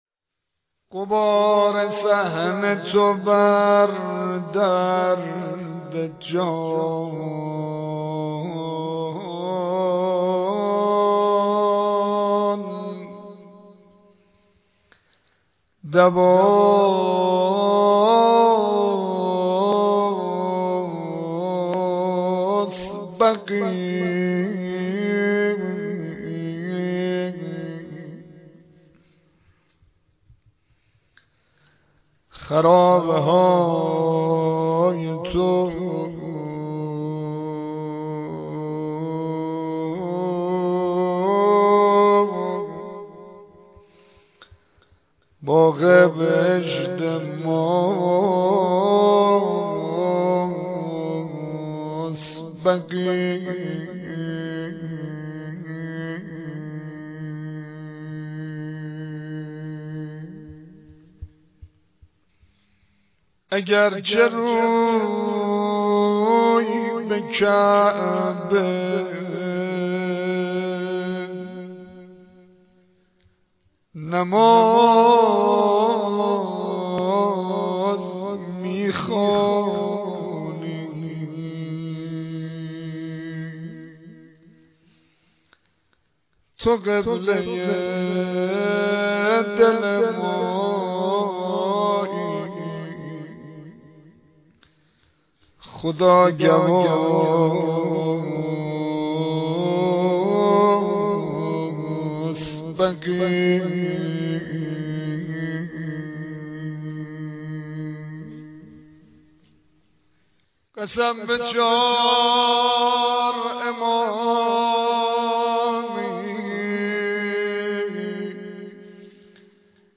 روضه وفات حضرت ام البنین